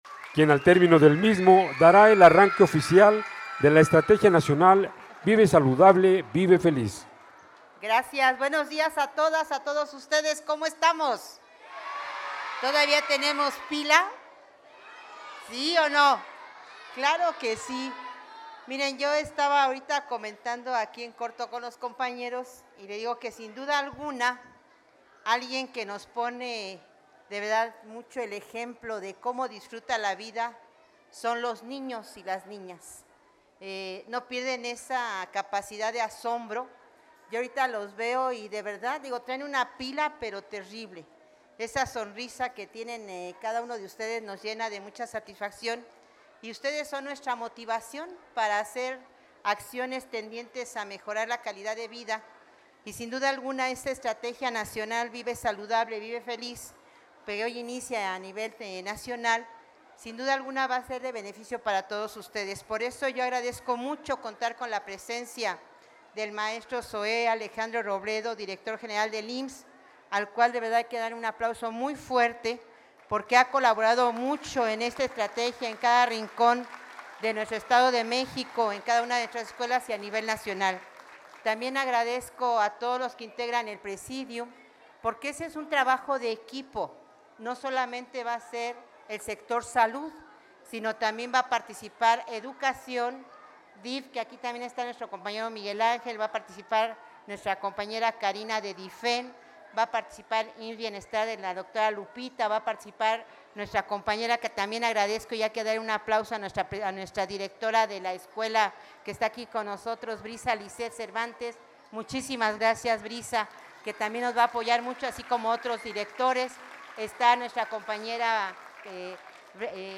MENSAJE-COMPLETO_AUDIO_DGA_ESTRATEGIA-NACIONAL-VIVE-SALUDABLE-VIVE-FELIZ.mp3